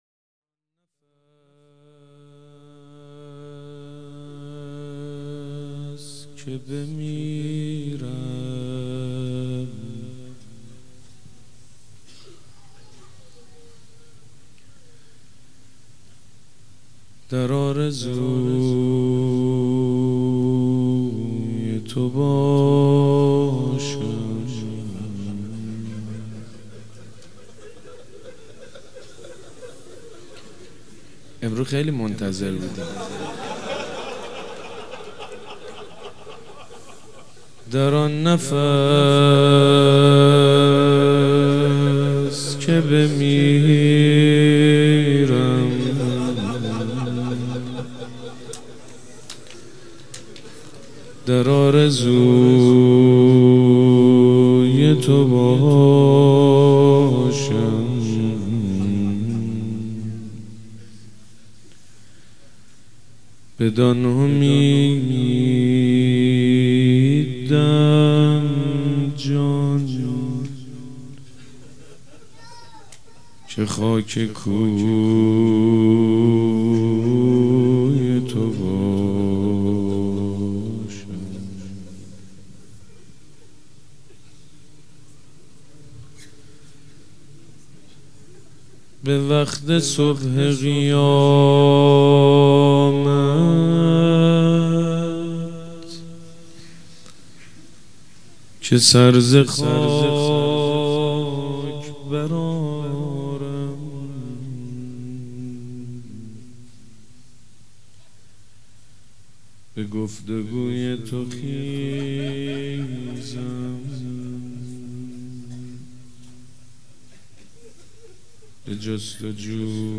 شب شهادت امام صادق علیه السلام
شعر خوانی